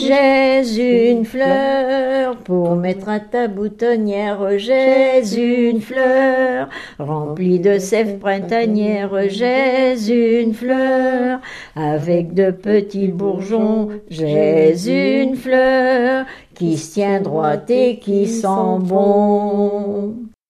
Mémoires et Patrimoines vivants - RaddO est une base de données d'archives iconographiques et sonores.
Genre brève
Catégorie Pièce musicale inédite